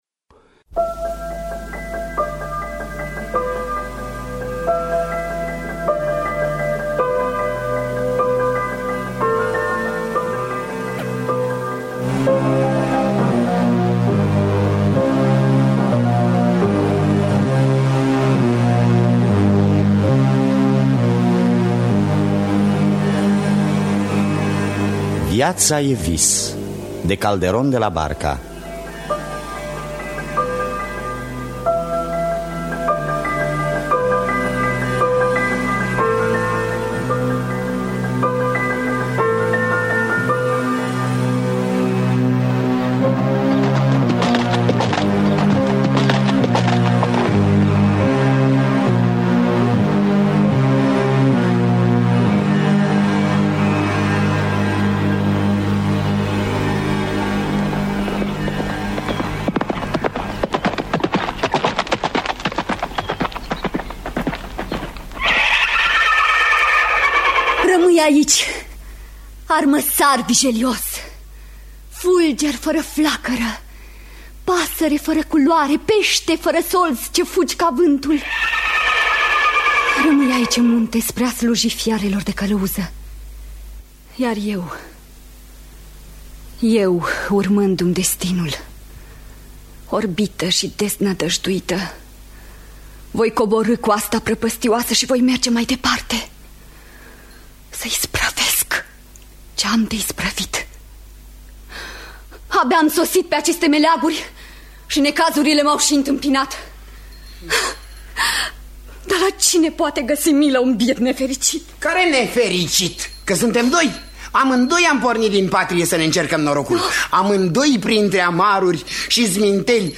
Adaptarea radiofonică de Vlaicu Bârna.